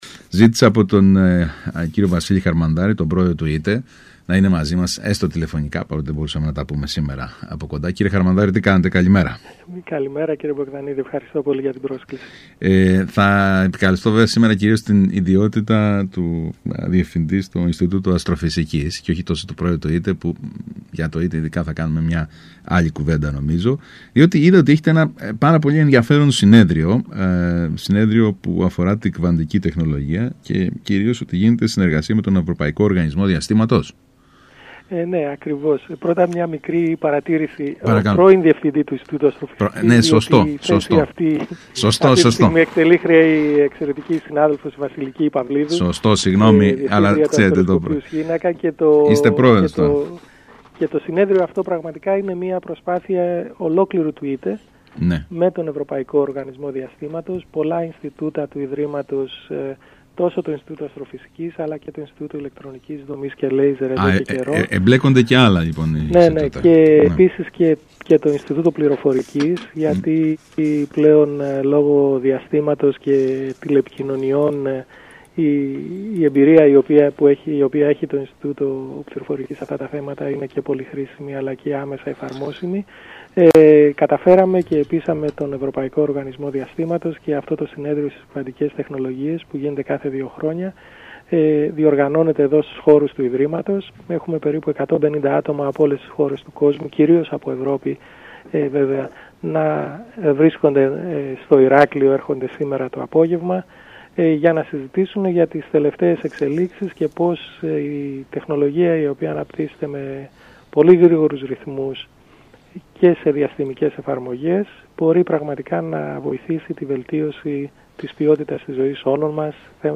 Ψήφο εμπιστοσύνης αποτελεί για την επιστημονική-ερευνητική κοινότητα της χώρας και το ΙΤΕ το 7ο Συνέδριο Κβαντικής Τεχνολογίας του Ευρωπαϊκού Οργανισμού Διαστήματος (ESA), που πραγματοποιείται στην Κρήτη στις 7-9 Οκτωβρίου 2025, με φετινό οικοδεσπότη το Ίδρυμα Τεχνολογίας και Έρευνας. Αυτό τόνισε μιλώντας στον ΣΚΑΙ Κρήτης